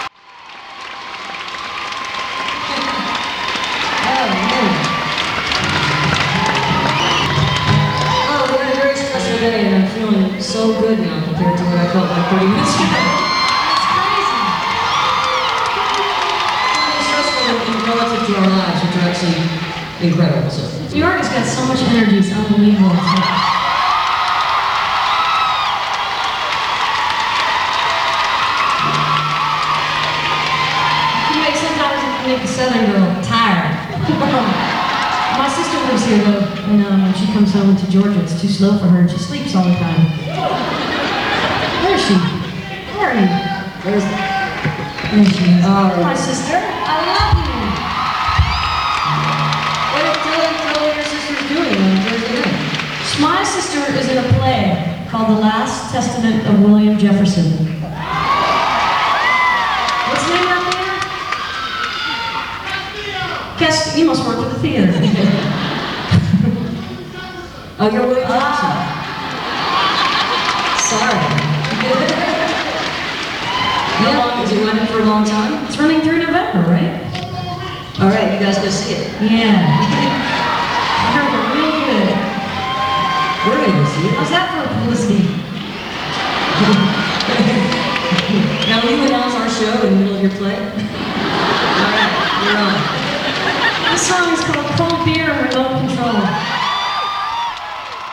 lifeblood: bootlegs: 1999-10-12: the beacon theater - new york, new york
08. talking with the crowd (1:38)